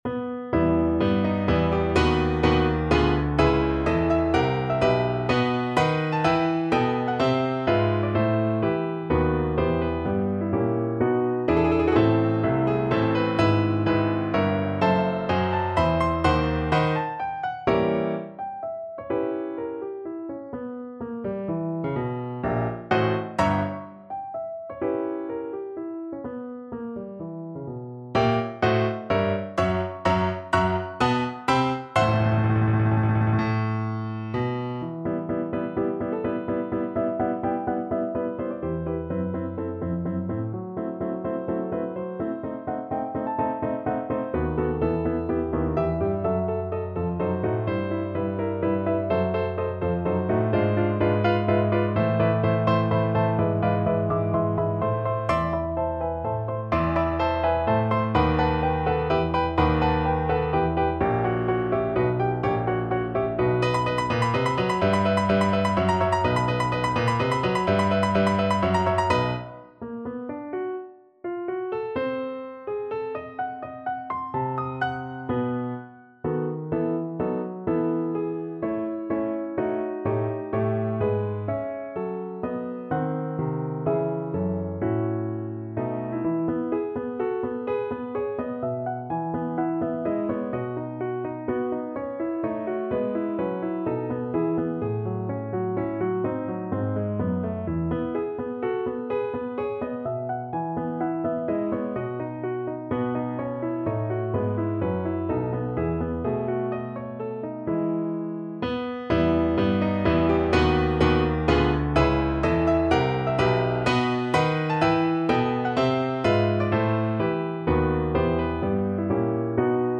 No parts available for this pieces as it is for solo piano.
Allegro maestoso =126 (View more music marked Allegro)
3/4 (View more 3/4 Music)
Piano  (View more Advanced Piano Music)
Classical (View more Classical Piano Music)